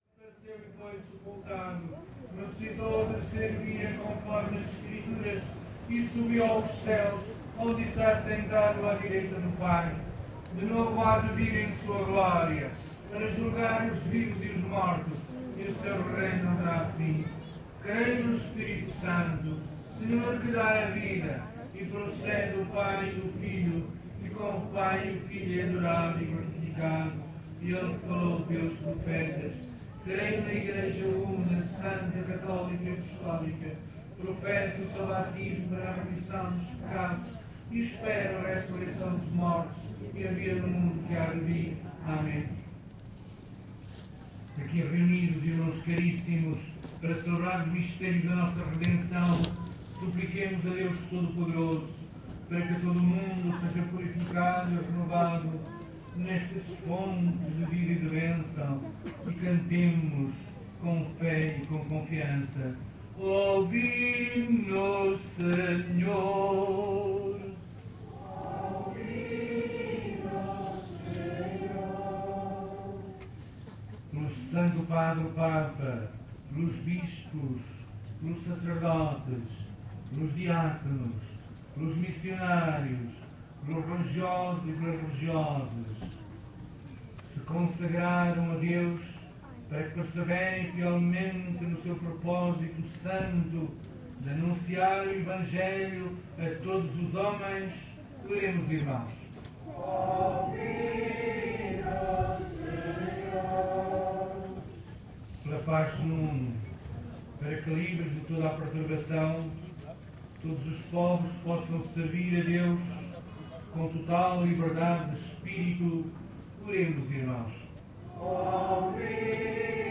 Gravação da missa anual dos peregrinos de São Macário. Gravado com Fostex FR-2LE e um par de microfones shotgun Rode NTG-2